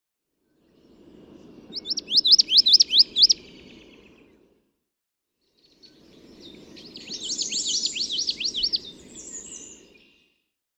Common yellowthroat
May 21, 2014. Parker River National Wildlife Refuge, Newburyport, Massachusetts (with ocean waves).
♫210—one song from each of two neighboring males
210_Common_Yellowthroat.mp3